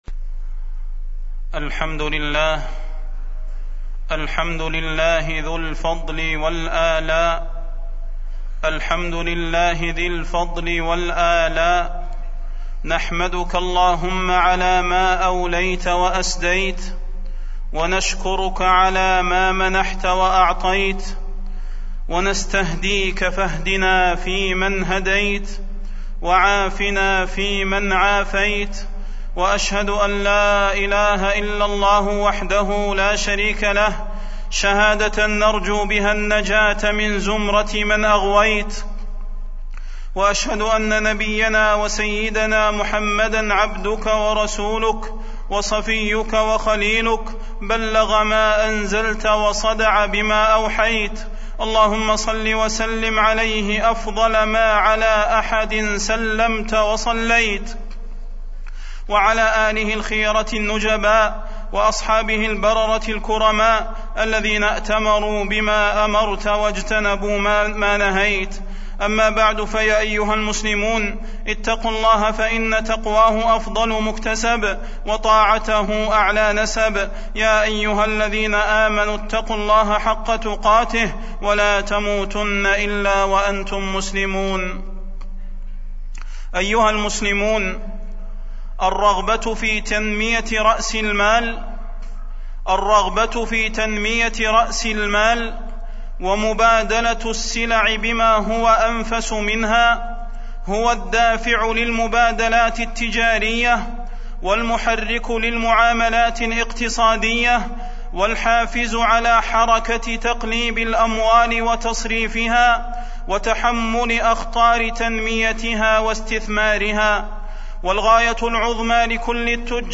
تاريخ النشر ١٧ صفر ١٤٢٧ هـ المكان: المسجد النبوي الشيخ: فضيلة الشيخ د. صلاح بن محمد البدير فضيلة الشيخ د. صلاح بن محمد البدير الربا واستثمار الأموال